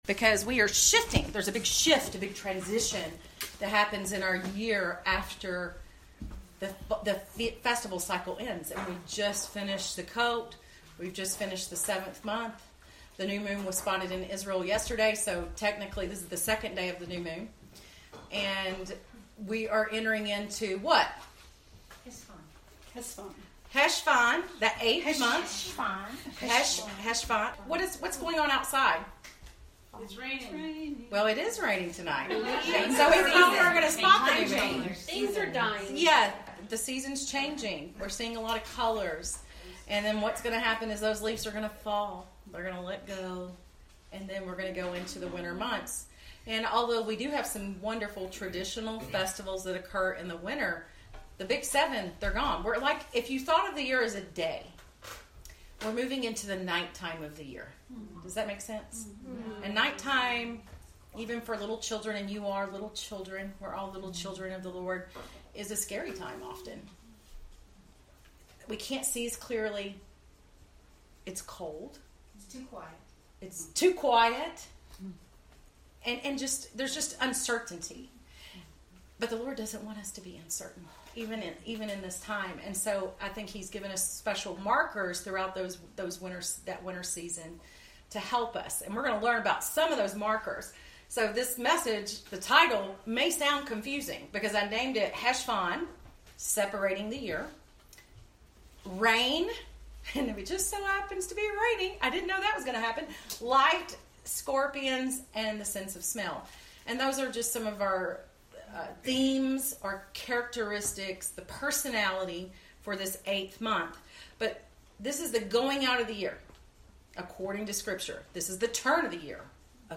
This is from a local gathering of women.